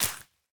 Minecraft Version Minecraft Version latest Latest Release | Latest Snapshot latest / assets / minecraft / sounds / block / cactus_flower / place3.ogg Compare With Compare With Latest Release | Latest Snapshot
place3.ogg